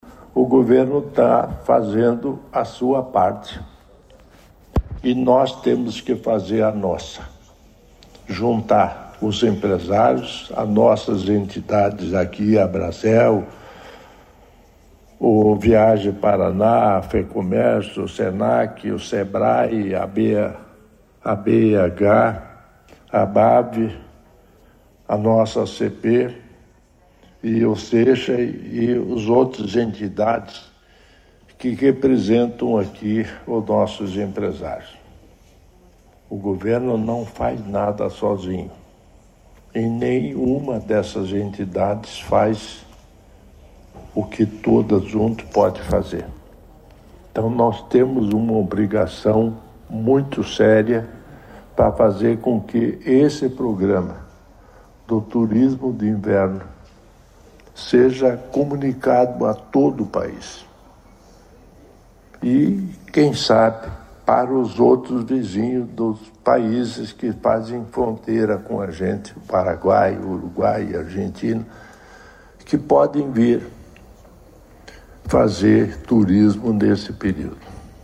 Sonora do governador em exercício Darci Piana sobre o fórum para fortalecer o turismo do inverno no Paraná